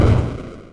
Techmino/media/effect/chiptune/fall.ogg at beff0c9d991e89c7ce3d02b5f99a879a052d4d3e